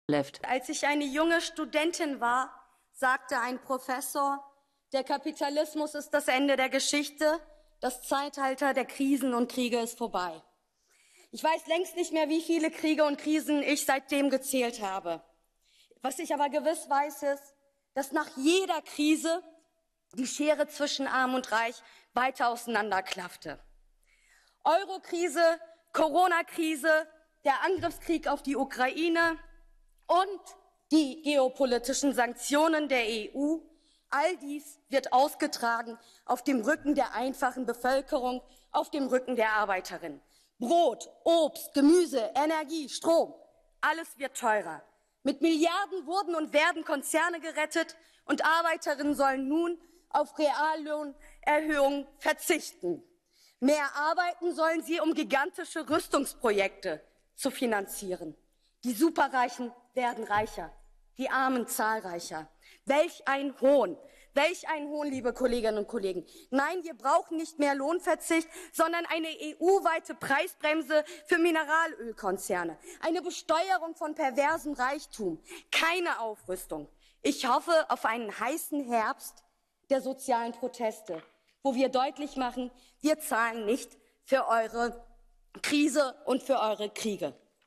Özlem Alev Demirel ist für Die Linke Abgeordnete im EU Parlament und hat vor kurzem eine beherzte Rede im Brüsseler Parlament über die aktuellen politischen Geschehnisse gehalten, welche die Situation auf den Punkt bringt!